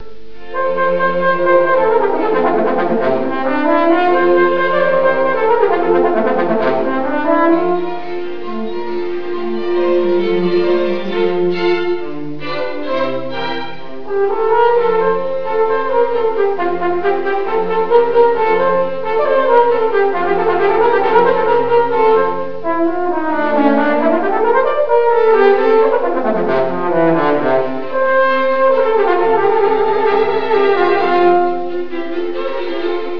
Hier einige Soundfiles, die einen Einblick in die Leistungen bei unserem letzten Klassenabend geben sollen.